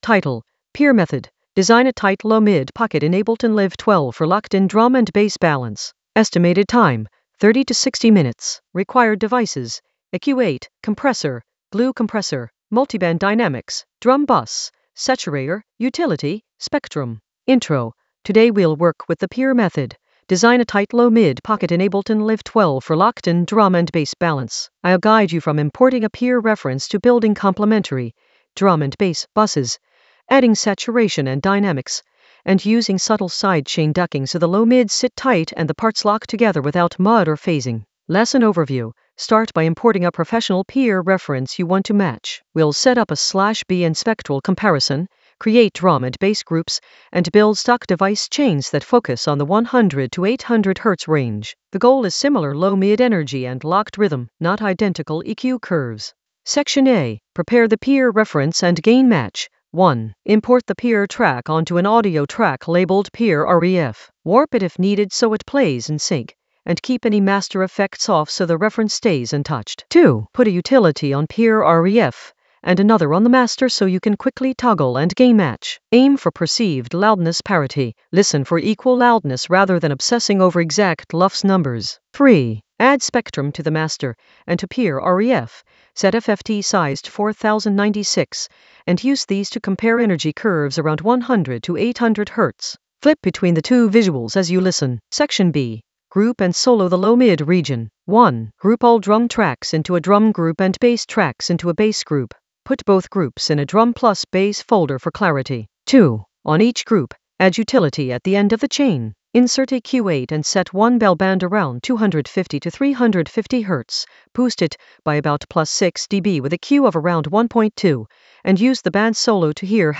An AI-generated intermediate Ableton lesson focused on Peer method: design a tight low-mid pocket in Ableton Live 12 for locked-in drum and bass balance in the Mixing area of drum and bass production.
Narrated lesson audio
The voice track includes the tutorial plus extra teacher commentary.